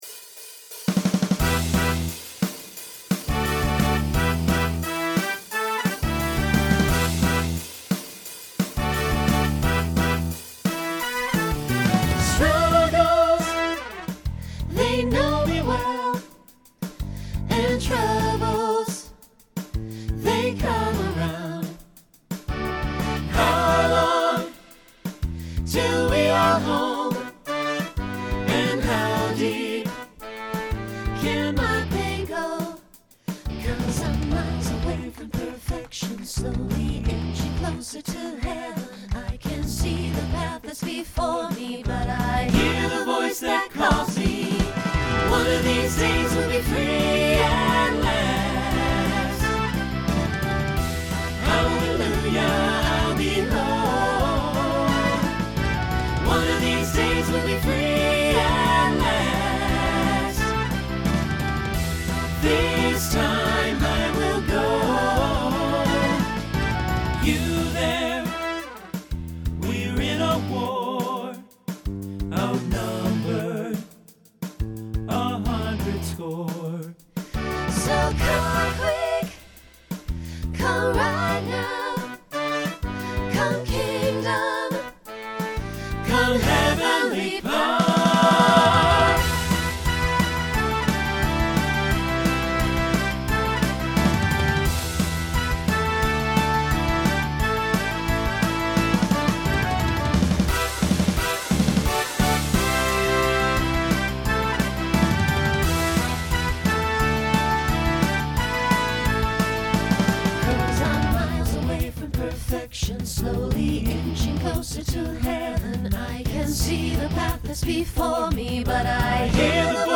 Genre Rock Instrumental combo
Voicing SATB